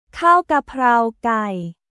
カーオ・ガパオ・ガイ